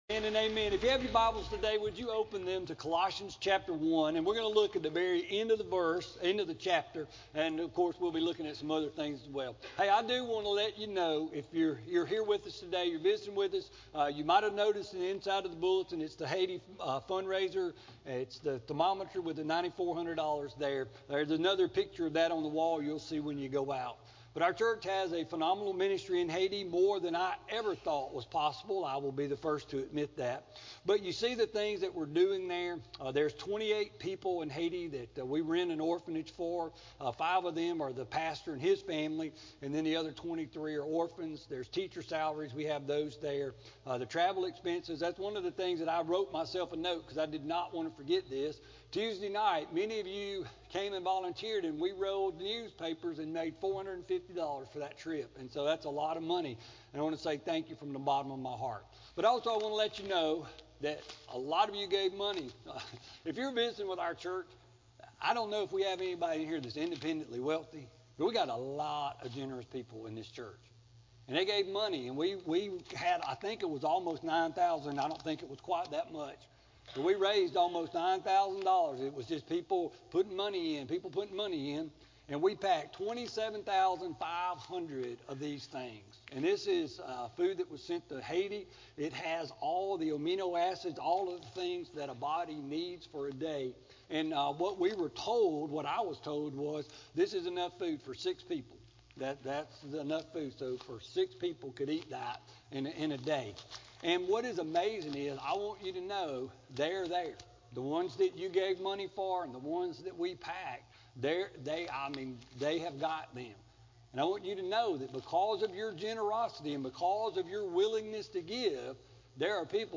Sermon-5-3-15-CD.mp3